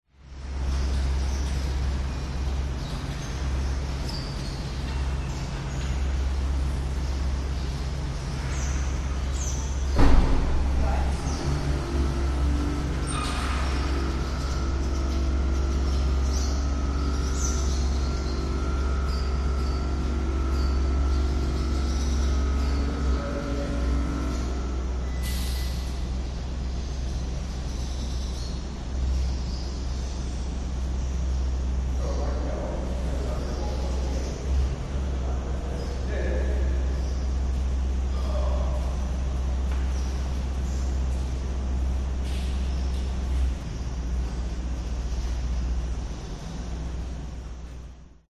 Звуки города
Звук подземного паркинга внутри с реверберацией мало людей работает насос для шин автомобилей птицы